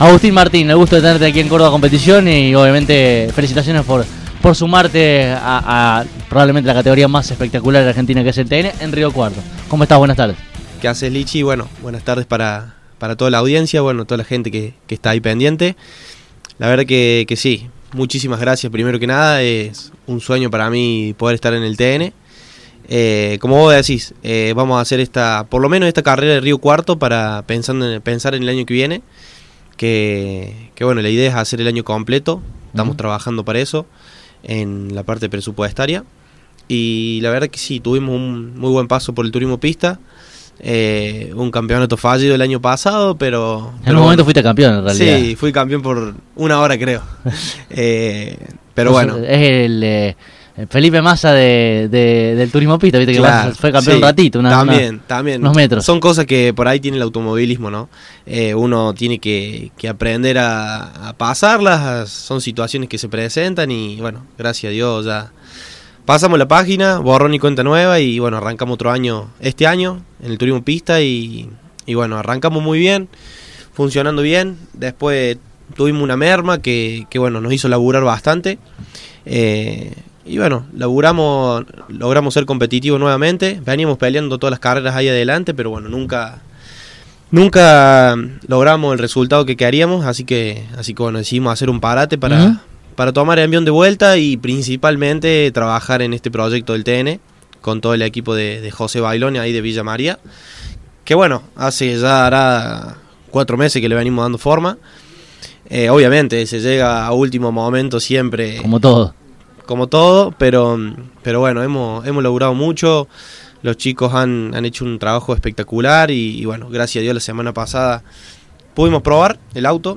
Escuchá aquí debajo la charla completa: